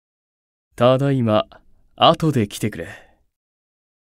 File:Lucifer Job Notification Voice.ogg
Lucifer_Job_Notification_Voice.ogg.mp3